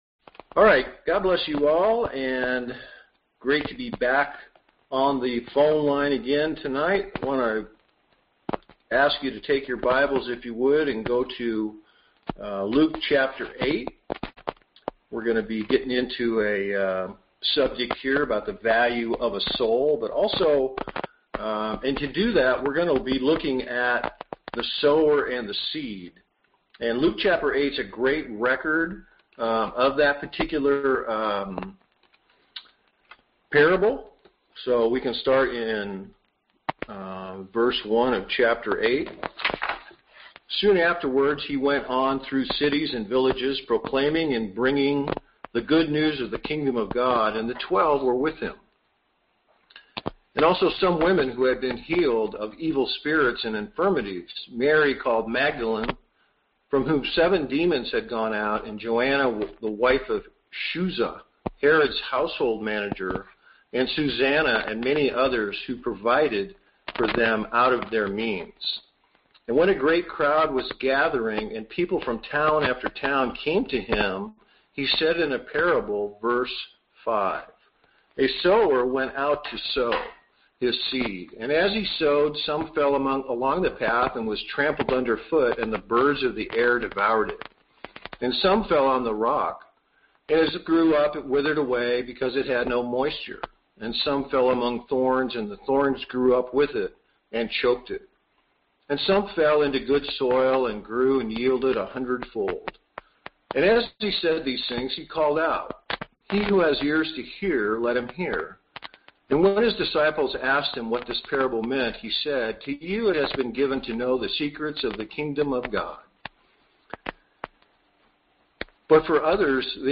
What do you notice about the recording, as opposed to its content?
Series: Conference Call Fellowship